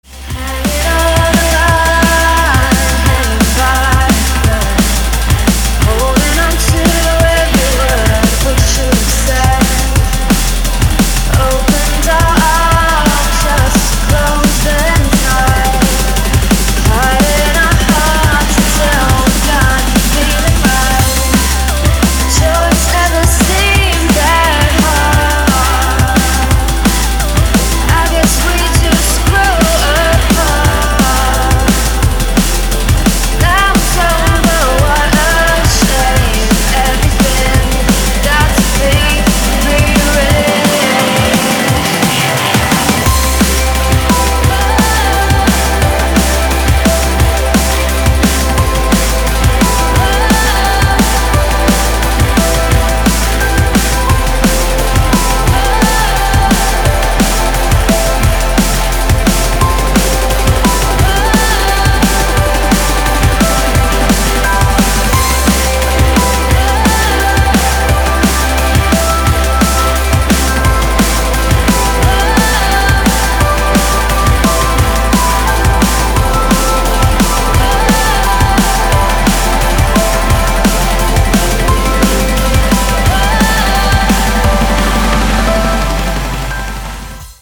• Качество: 320, Stereo
громкие
женский вокал
мелодичные
электронная музыка
клавишные
drum n bass